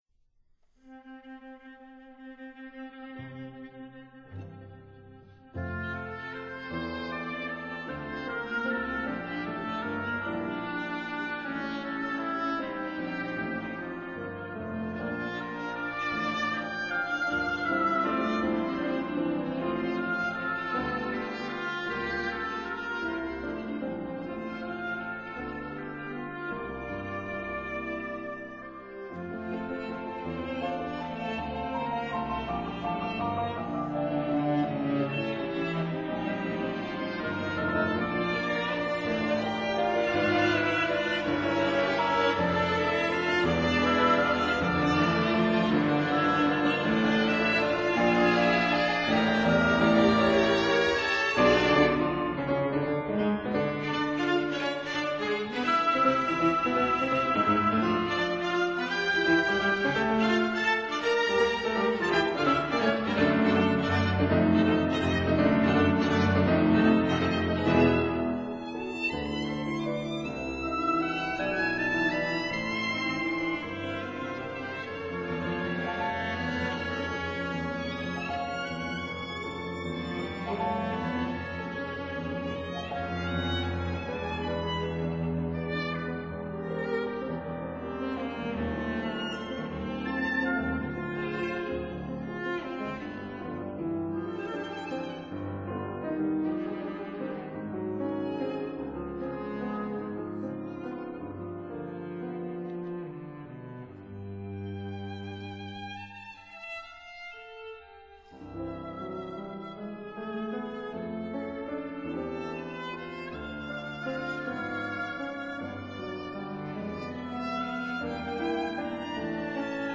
violin
cello
piano &
viola
oboe